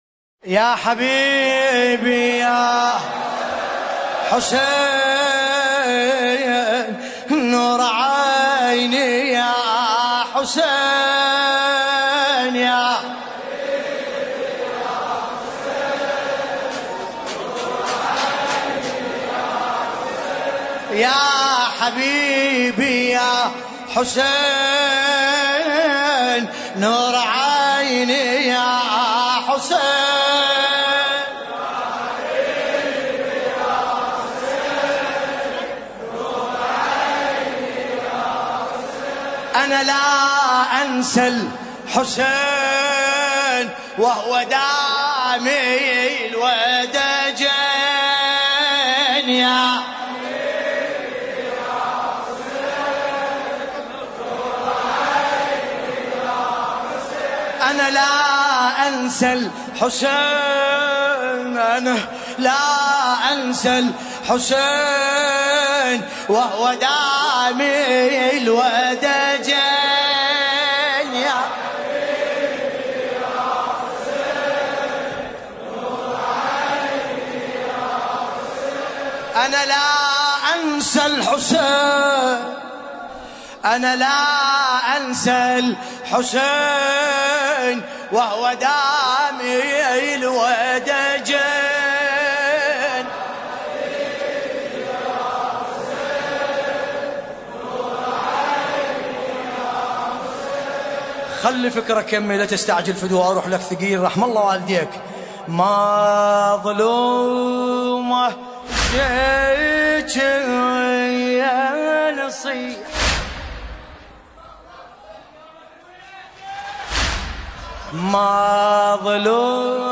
ملف صوتی مظلومة الزجية بصوت باسم الكربلائي